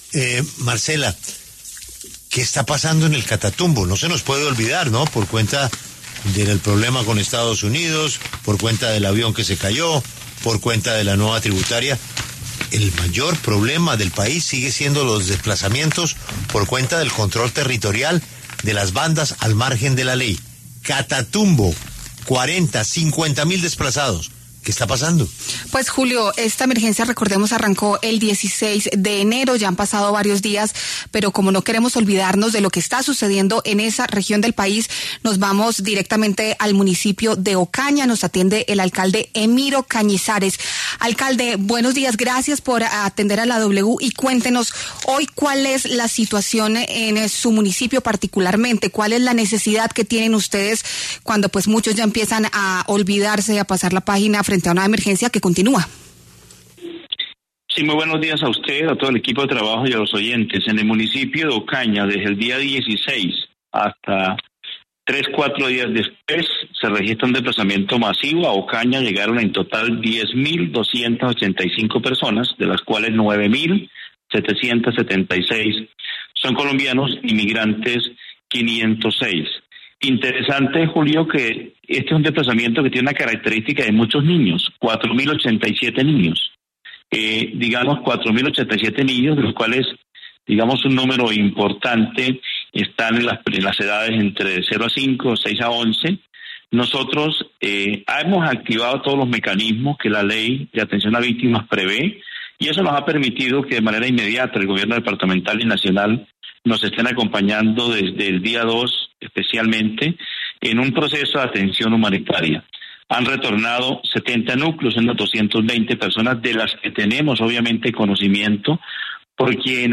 Emiro Cañizares, alcalde de Ocaña, pasó por los micrófonos de La W para hablar sobre la situación de desplazamiento por cuenta de los enfrentamientos entre el ELN y las disidencias de las Farc en el Catatumbo.